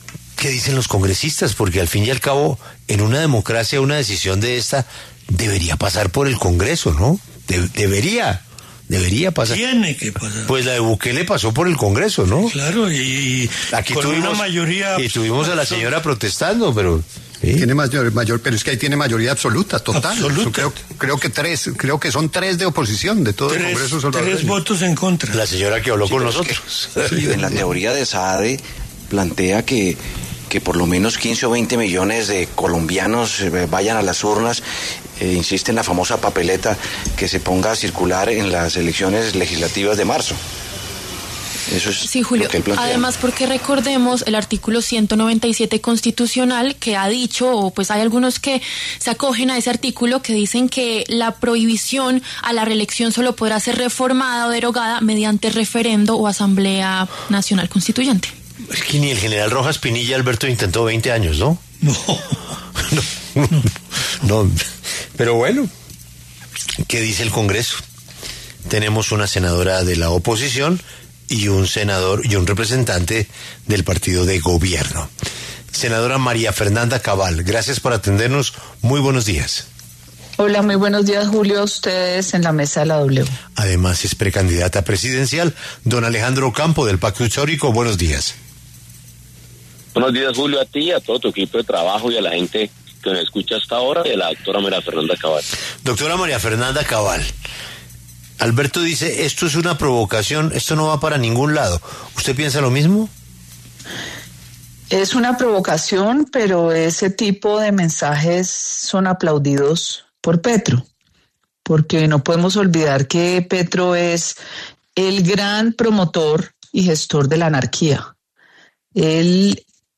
¿Reelegir a Petro por los próximos 20 años? Cabal y Ocampo debaten sobre propuesta de Saade
La senadora María Fernanda Cabal, del Centro Democrático y el representante Alejandro Ocampo, del Pacto Histórico, pasaron por los micrófonos de La W.